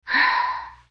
sigh2.wav